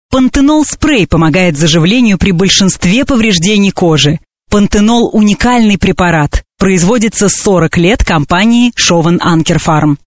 Professional female russian voice over artist.
Sprecherin russisch für TV / Rundfunk / Industrie.
Sprechprobe: Industrie (Muttersprache):